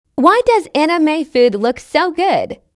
Animefood-tts.mp3